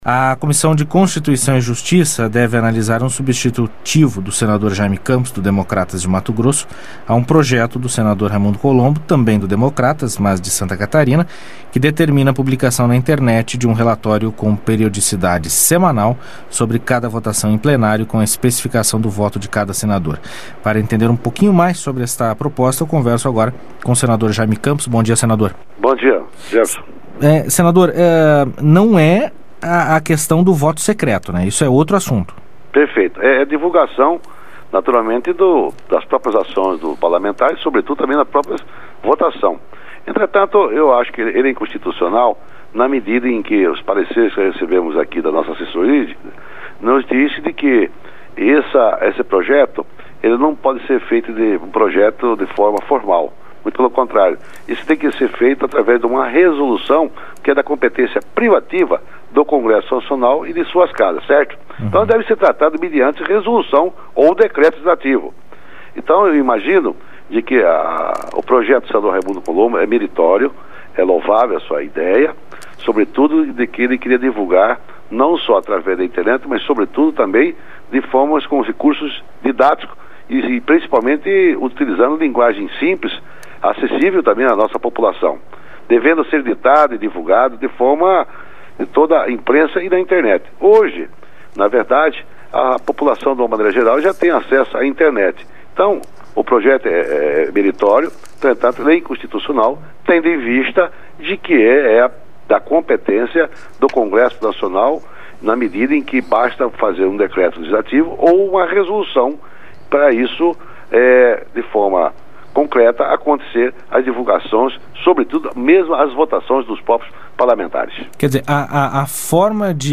Entrevista com o senador Jayme Campos (DEM- MT), relator da proposta do senador Raimundo Colombo (DEM-SC) que determina a publicação, na Internet, de um relatório com periodicidade no mínimo semanal, sobre cada votação em Plenário, com especificação do voto de cada senador.